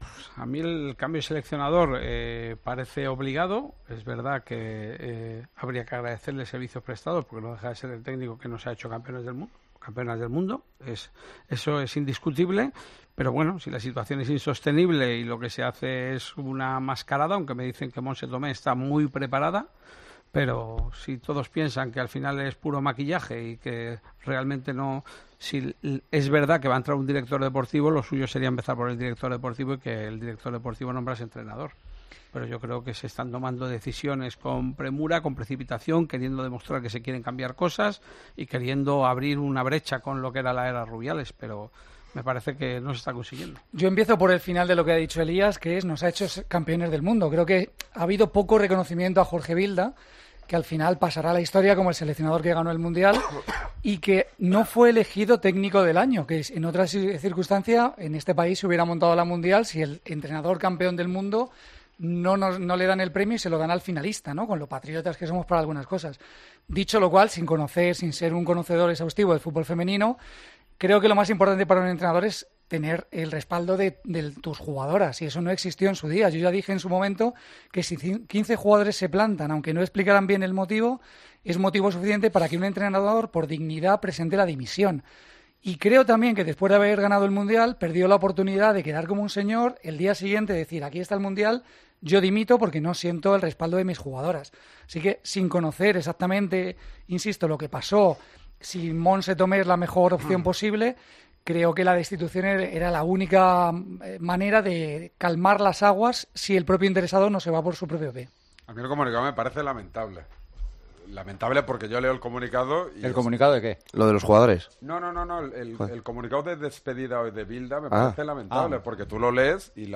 Los tertulianos del programa